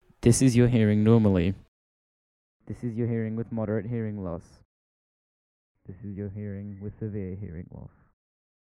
deaf hearing loss sound effect free sound royalty free Sound Effects